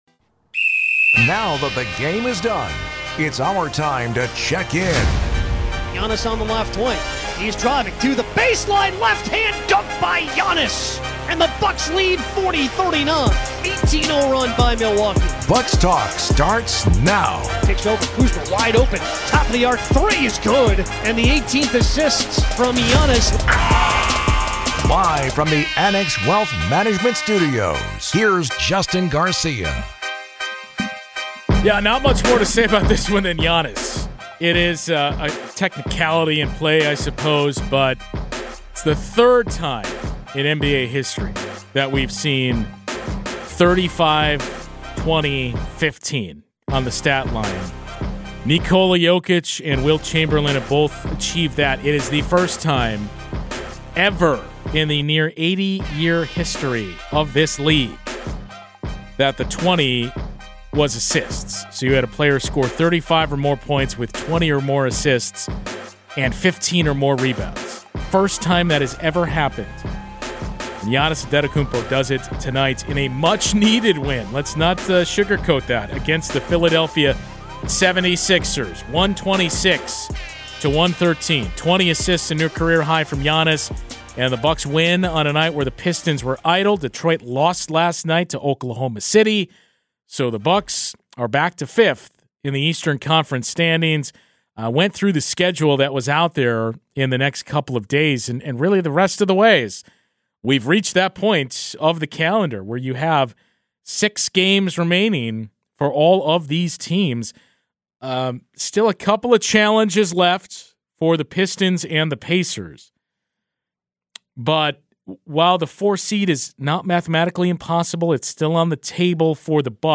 on the BMO Bucks radio network prior to a pivotal matchup against New York to chat about the team's tough road trip and much more.